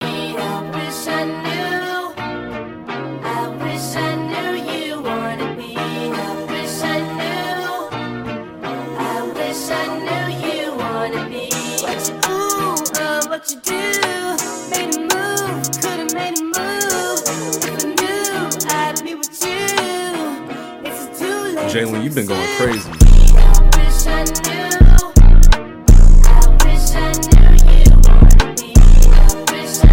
Жанр: Рэп и хип-хоп / Иностранный рэп и хип-хоп
# Hip-Hop